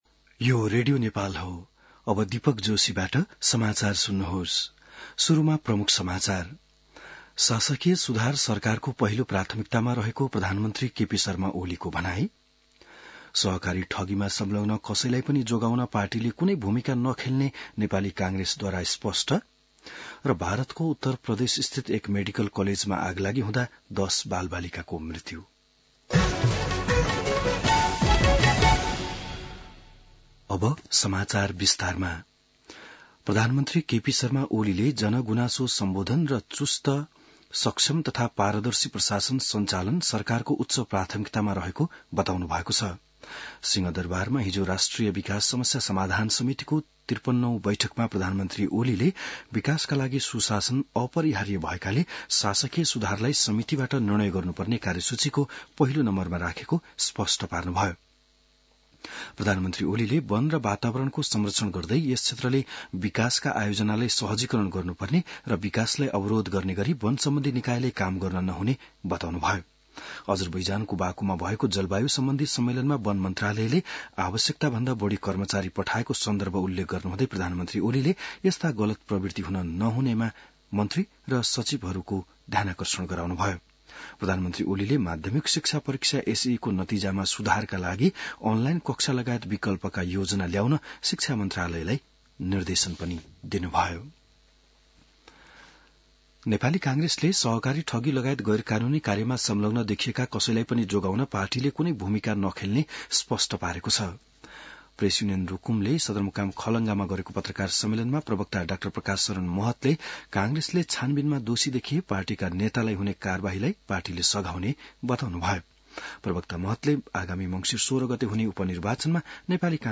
बिहान ९ बजेको नेपाली समाचार : २ मंसिर , २०८१